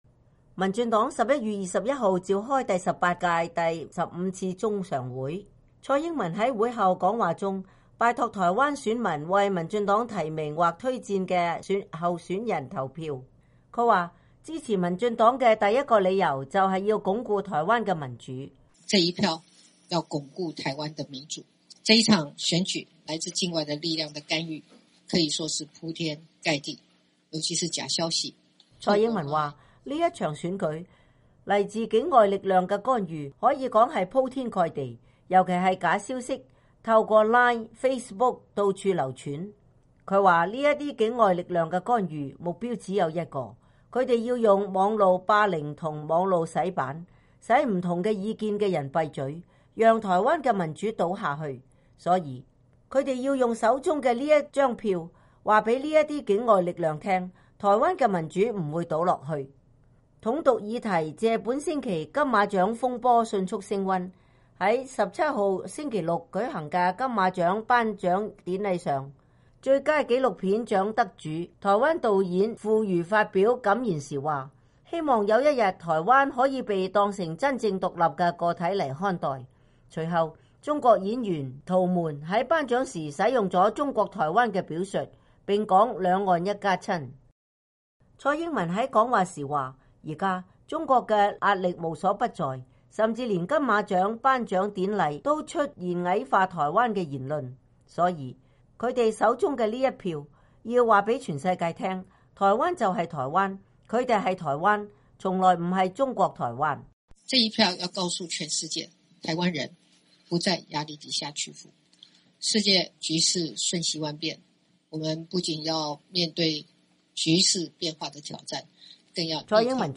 台灣“九合一”地方選舉進入倒數三天的階段，台灣總統、民主進步黨主席蔡英文星期三在一次拉票講話中說，即使面臨境外力量的干預，台灣民主不會倒。